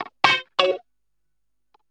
WAV guitarlicks